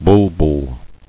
[bul·bul]